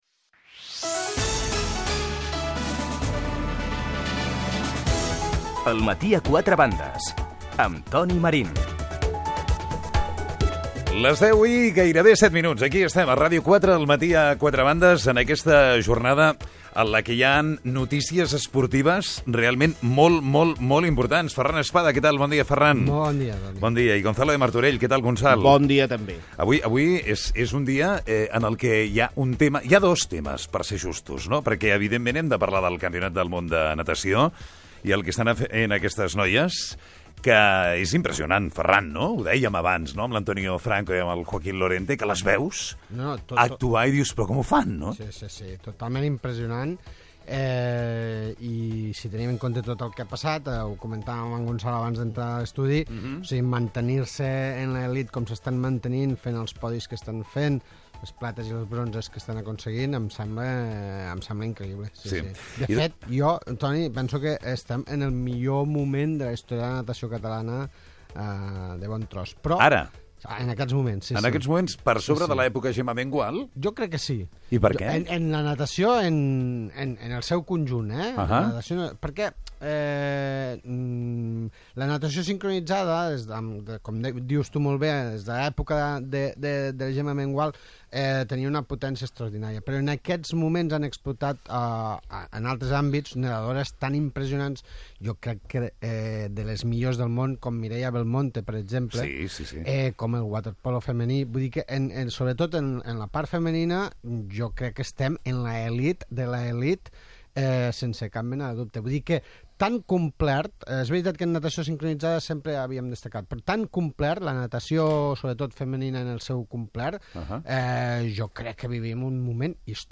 tertúlia esportiva: els bons resultats de la natació i el waterpolo femení, la substitució de l'entrenador del Futbol Club Barcelona Tito Vilanova per malatia.